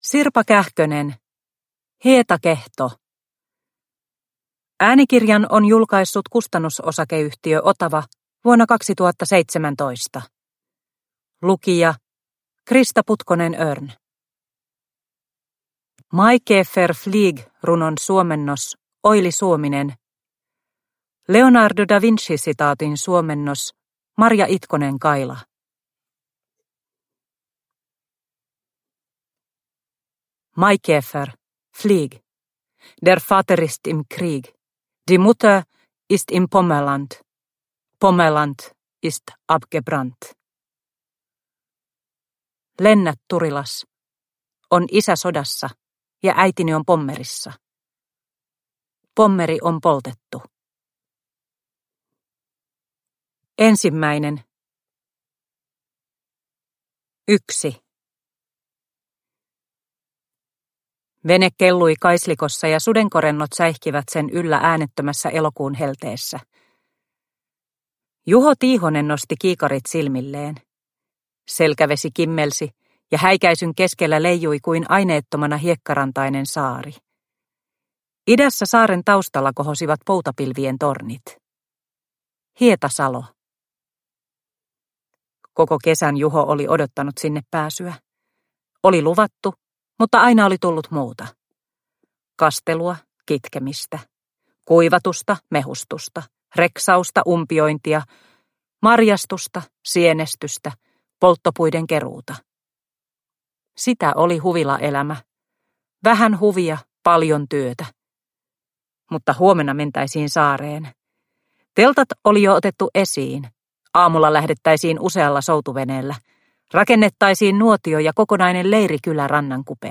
Hietakehto – Ljudbok – Laddas ner